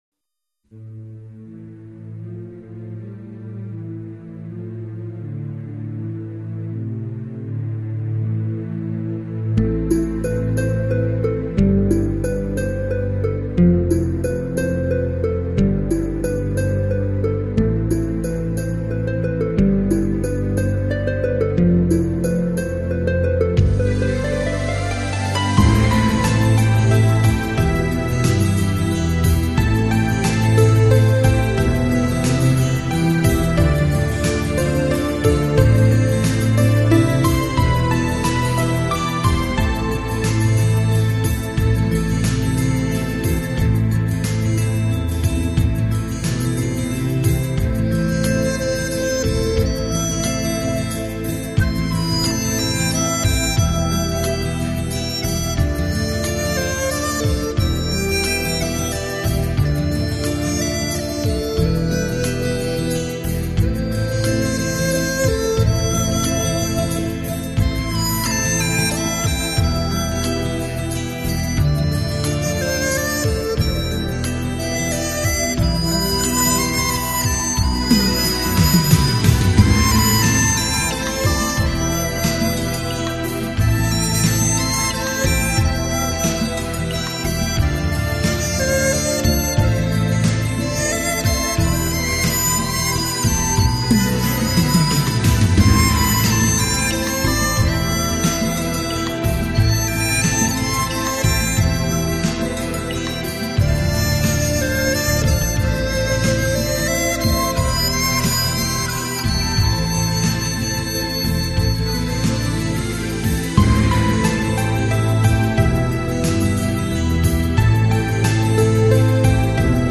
【笛子专辑】
笛子是吹奏乐器。